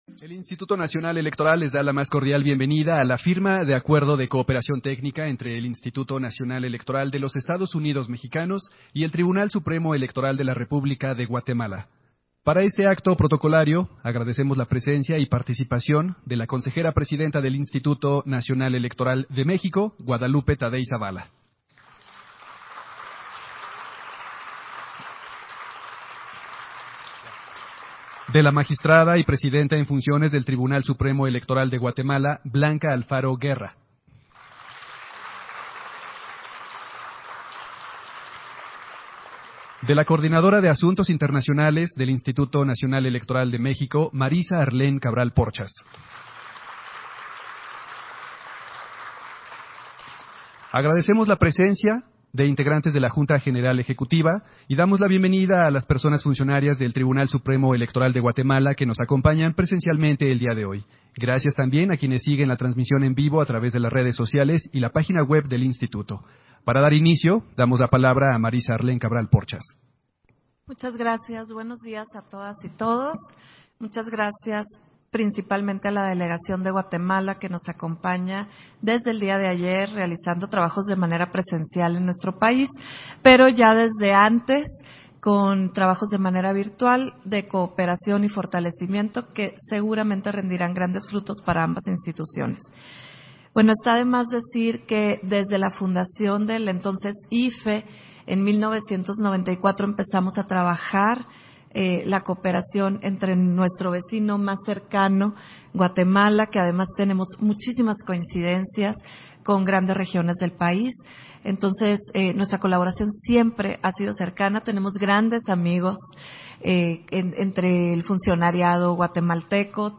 Firma de Acuerdo de Cooperación Técnica entre el Instituto Nacional Electoral de los Estados Unidos Mexicanos y el Tribunal Supremo Electoral de la República de Guatemala.